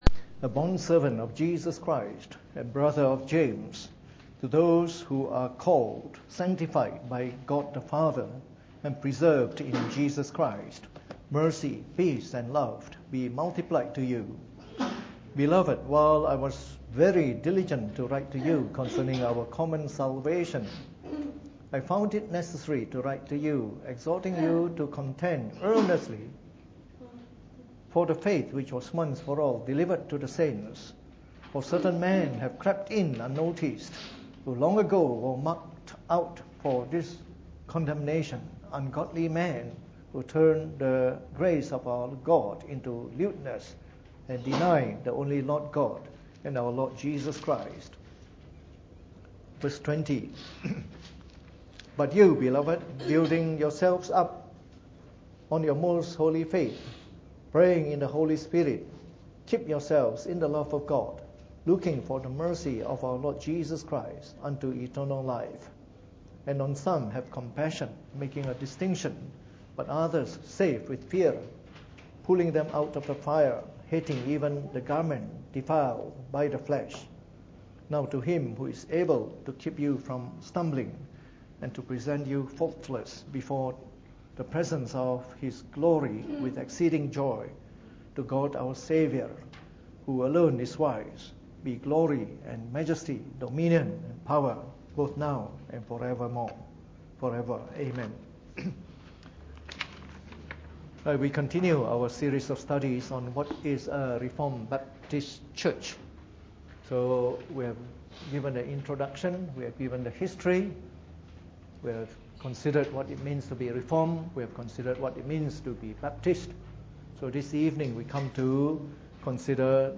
Preached on the 14th of June 2017 during the Bible Study, from our series on Reformed Baptist Churches.